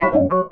Key-organ-03_001.wav